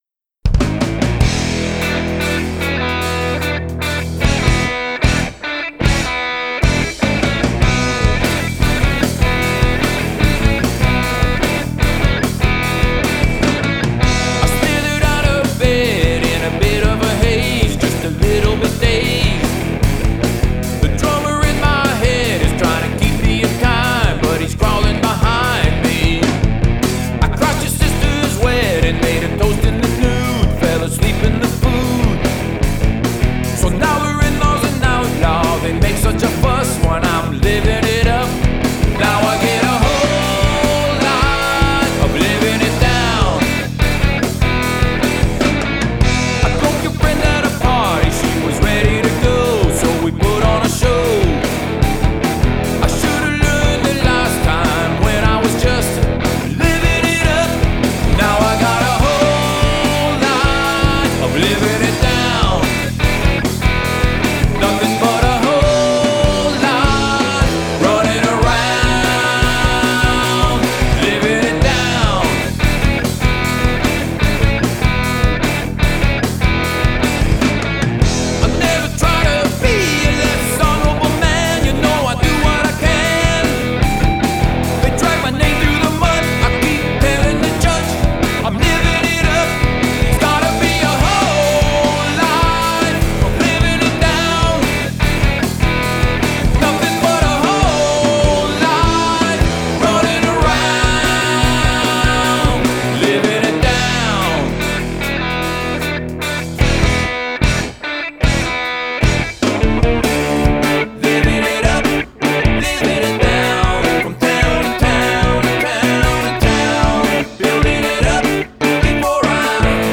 Funny that I could barely hear a difference in sound or any reduction, but with the compressor, the song averages about -12.5. But without the compressor it averages about -11.0. So, the compressor is definitely "taming" in some way. I guess the mix sounds more "glued" together, but I'm hoping those of you with better ears for this can tell me if it's pumping too much or choking the mix too much.
Attack 20ms Release 100ms.